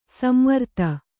♪ samvarta